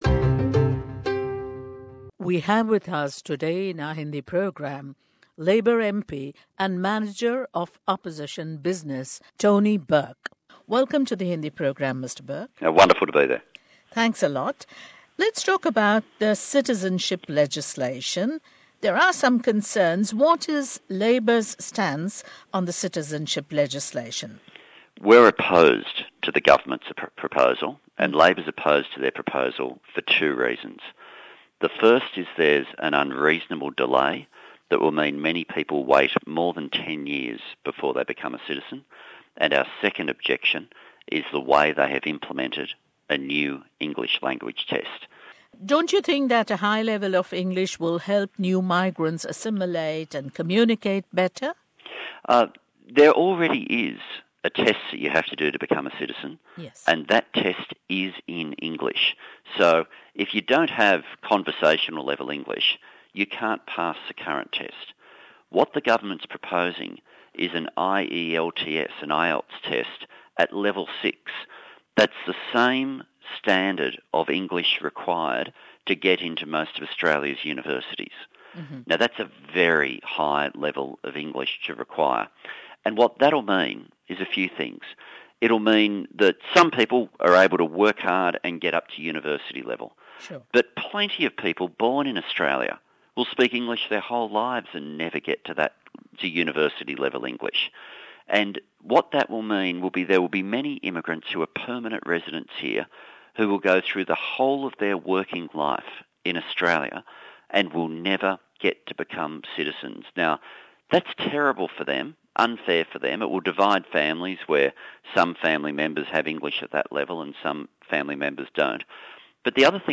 Tony Burke tells SBS Hindi that there is a "dreadful double standard" in the proposed changes to Australia's citizenship laws that deliberately disadvantages those that have not come from majority-white countries.